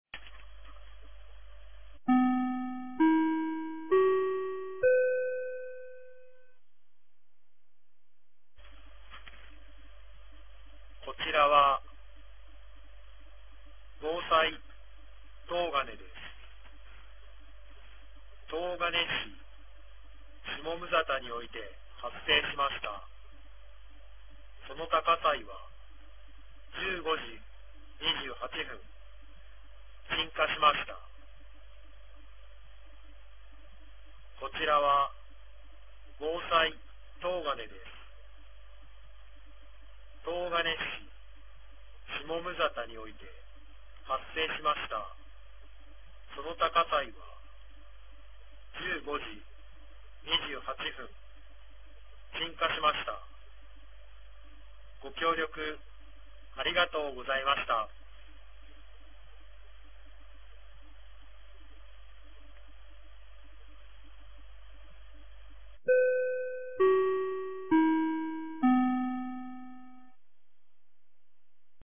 2024年12月29日 15時35分に、東金市より防災行政無線の放送を行いました。